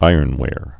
(īərn-wâr)